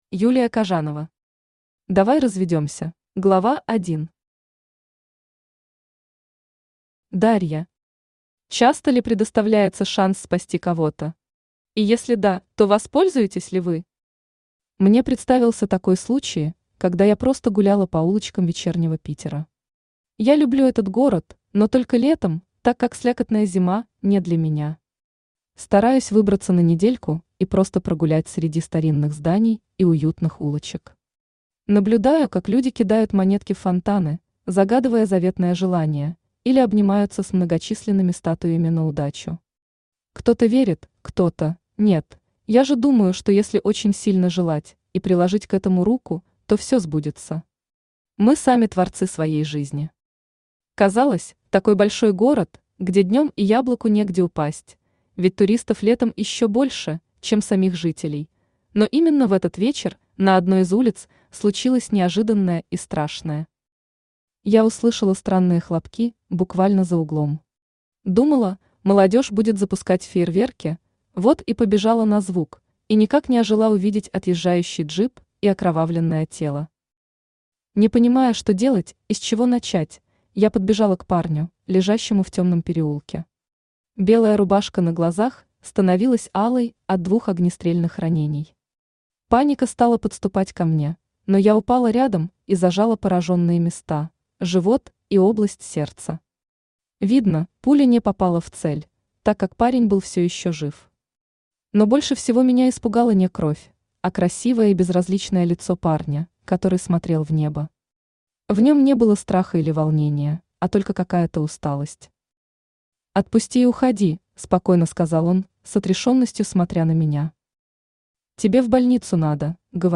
Аудиокнига Давай разведёмся | Библиотека аудиокниг
Aудиокнига Давай разведёмся Автор Юлия Витальевна Кажанова Читает аудиокнигу Авточтец ЛитРес.